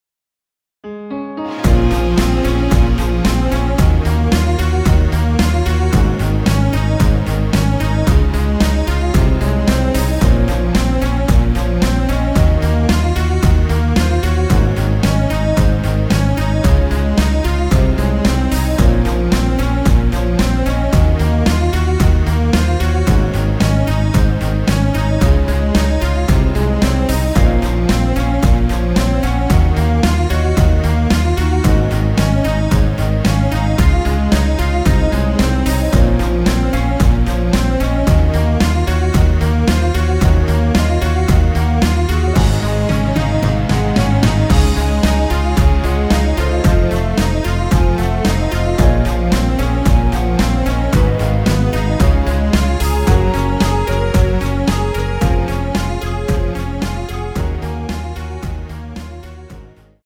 원키 멜로디 포함된 MR 입니다.(미리듣기 참조)
Db
앞부분30초, 뒷부분30초씩 편집해서 올려 드리고 있습니다.
중간에 음이 끈어지고 다시 나오는 이유는